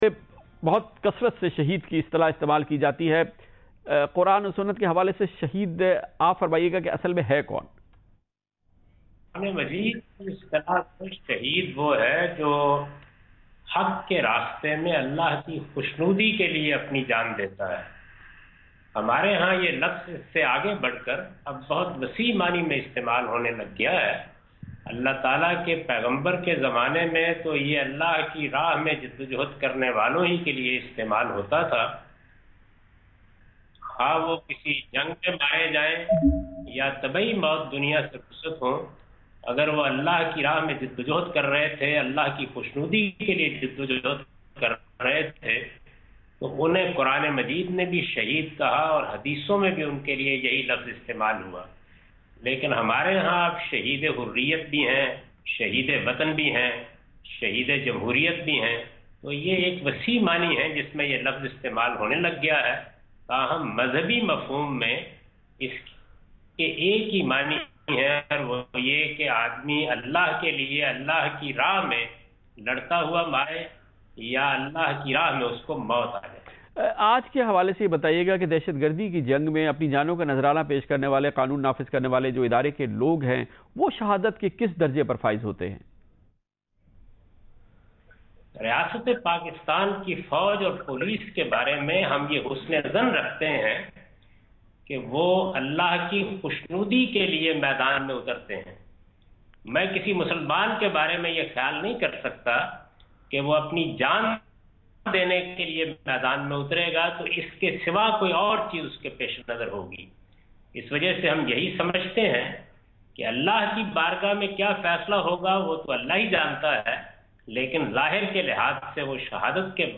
Category: TV Programs / Geo Tv / Questions_Answers /
Javed Ahmad Ghamidi is Commenting on Who is a Martyr? on Geo Tv in Kamaran Khan Kay Saath.
جیو ٹی وی کہ پروگرام کامران خان کے ساتھ میں جاوید احمد غامدی"شہید کون ہوتا ہے" کے متعلق ایک سوال کا جواب دے رہے ہیں